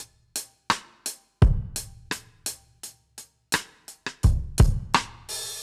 Db_DrumsA_Dry_85-02.wav